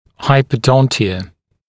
[ˌhaɪpəˈdɔntɪə][ˌхайпэˈдонтиэ]гиподонтия (врожденное или приобретенное отсутствие зубов)